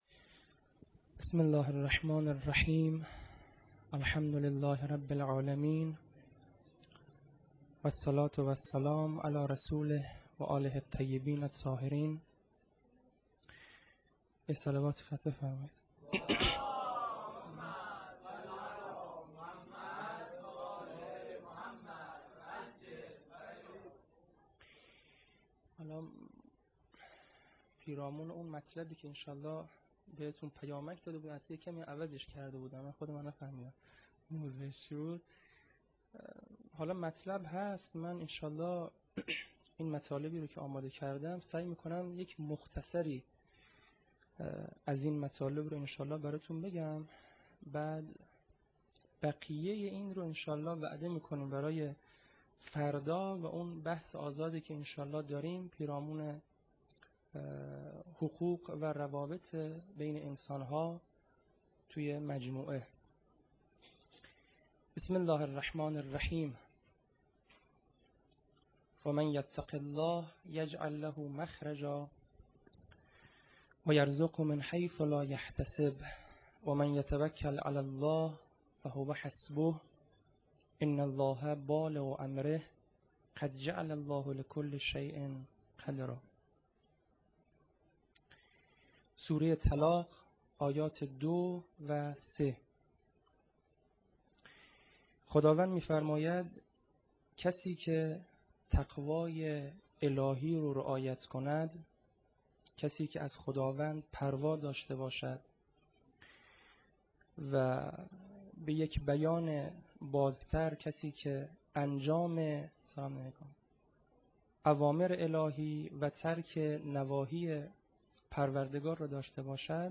سخنرانی
هیات هفتگی